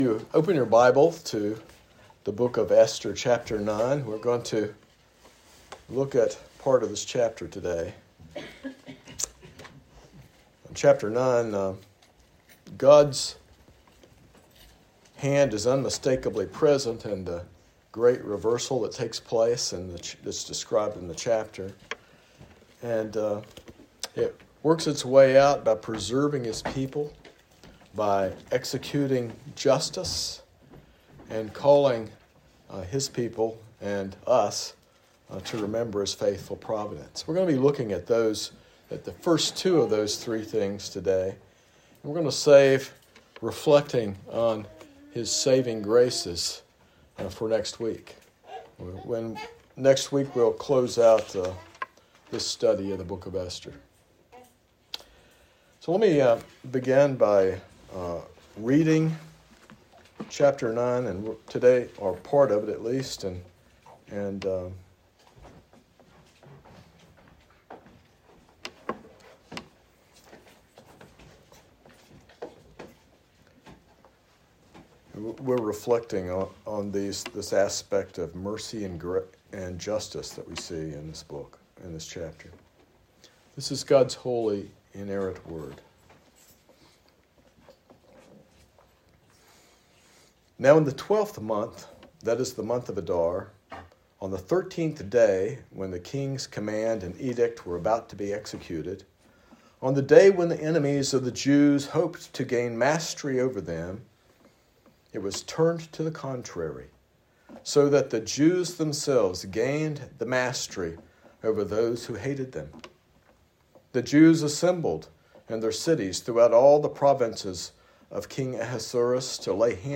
This sermon explores Esther chapter 9, highlighting God’s unmistakable hand in history through his providence, mercy, and justice. It emphasizes that God orchestrates events, even apparent coincidences, to fulfill his purposes and care for his people, ultimately demonstrated through Christ’s sacrifice on the cross.